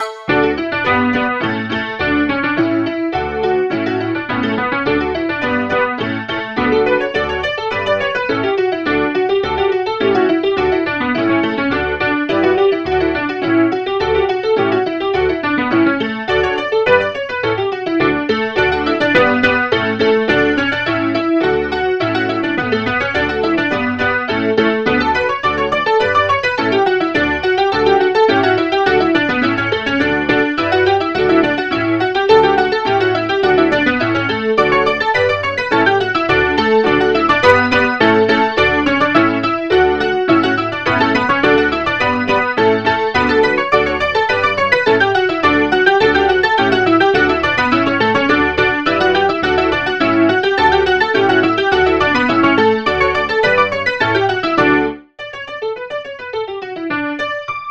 Midi File, Lyrics and Information to Arkansas Traveler